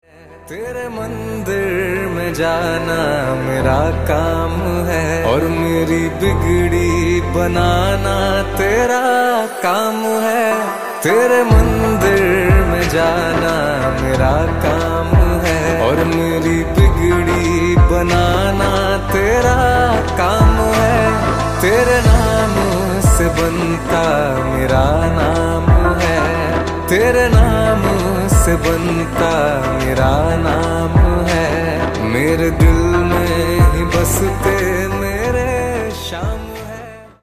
Hindi Songs